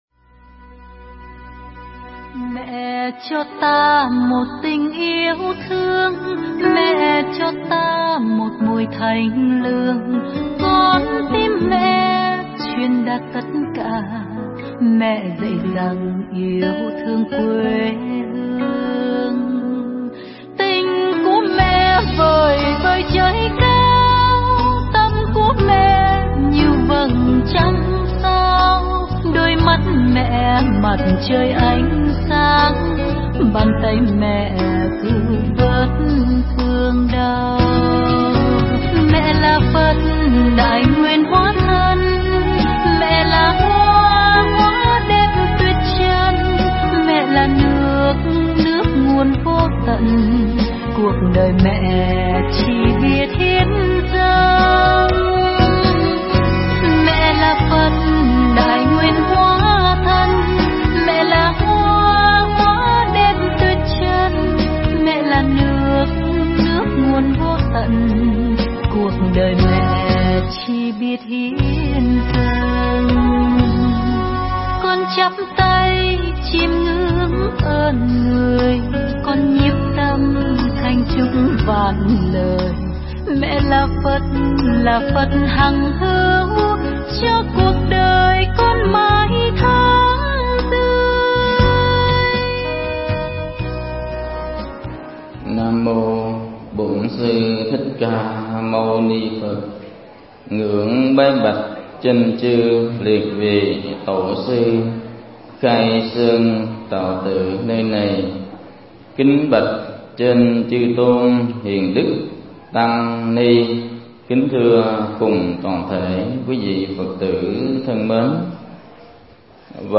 Mp3 Thuyết Pháp Vu Lan Qua Những Vần Thơ
giảng tại Chùa Linh Sơn, Thống Nhất, Tỉnh Đồng Nai